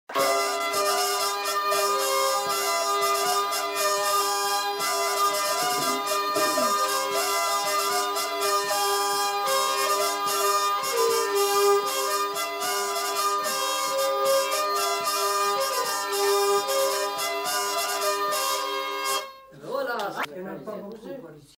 Aire culturelle : Limousin
Lieu : Lacombe (lieu-dit)
Genre : morceau instrumental
Instrument de musique : violon ; vielle à roue
Danse : bourrée
Notes consultables : La vielle à roue est jouée par un des enquêteurs.